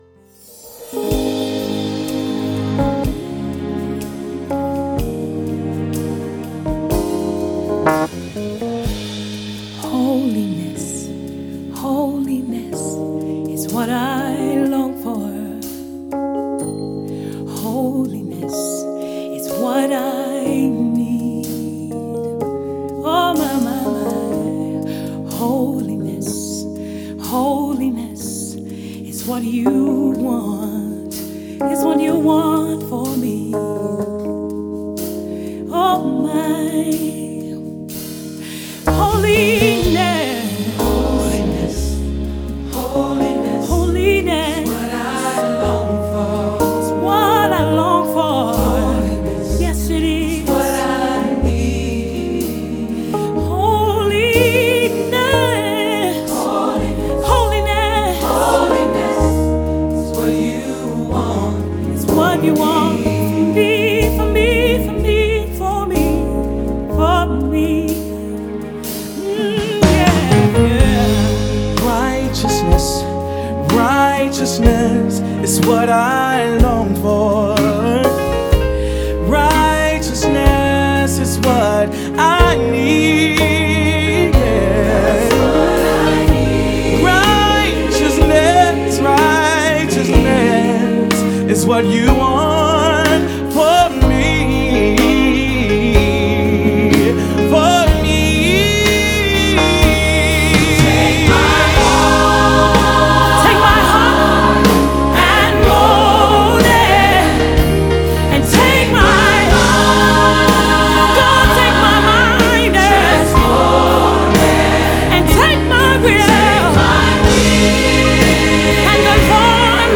장르: Funk / Soul, Pop
스타일: Gospel, Vocal